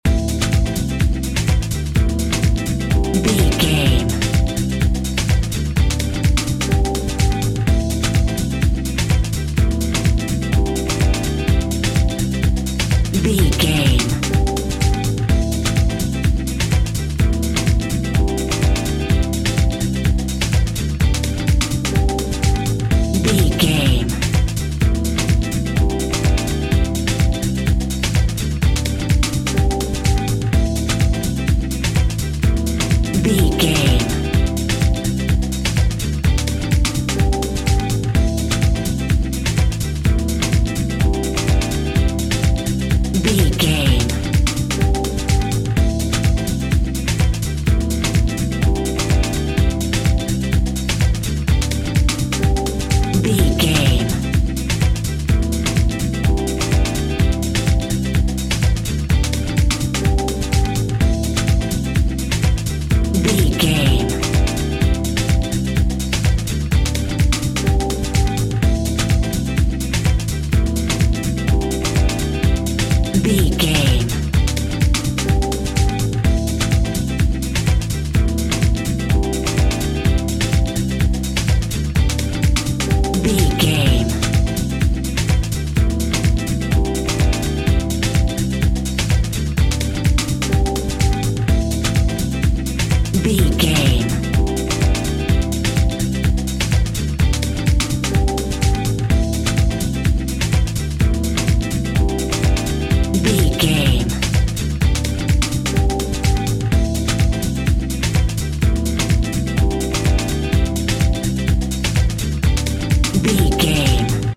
Ionian/Major
funky
groovy
uplifting
driving
energetic
synthesiser
electric piano
acoustic guitar
bass guitar
drums
house
dance
electro
electro house
synth drums
synth leads
synth bass